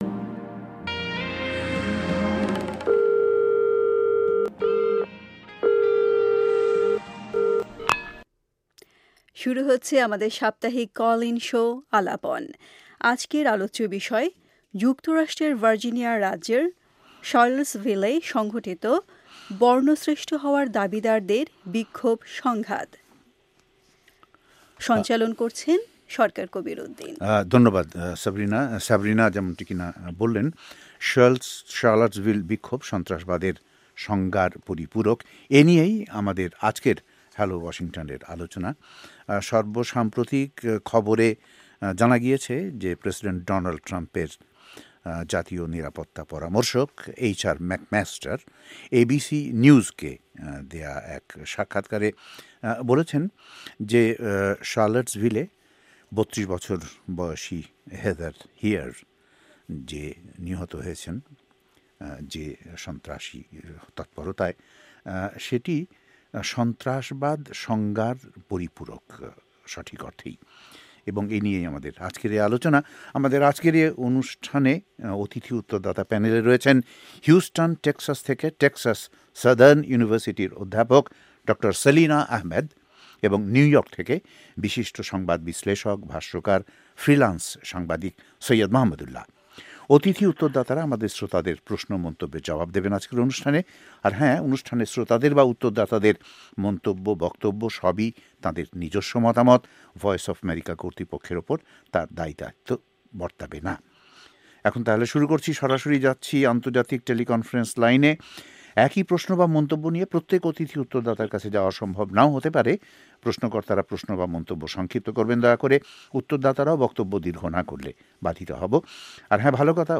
আজকের আলাপনের বিষয় 'শার্লটসভিলের বিক্ষোভ সন্ত্রাসবাদের সংজ্ঞার পরিপূরক’। আমাদের আজকের অনুষ্ঠানে অতিথি উত্তরদাতা প্যানেলে রয়েছেন